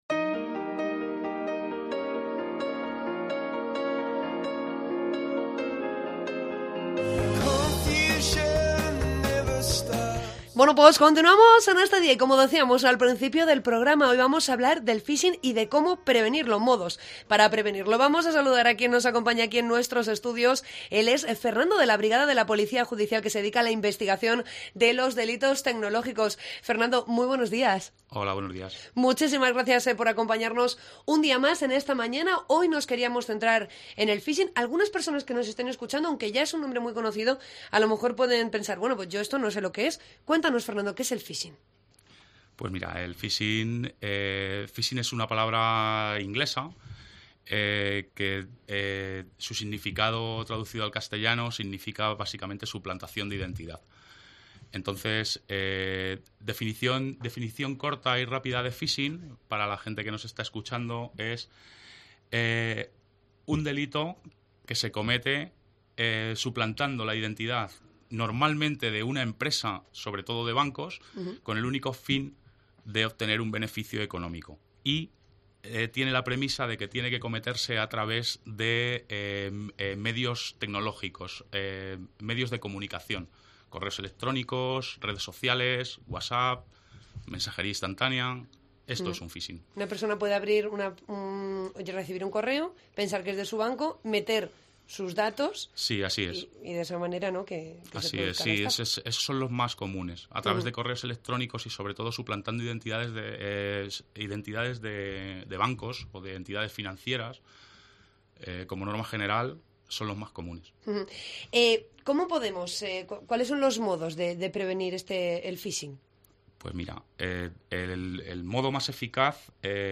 Entrevista sobre 'phising'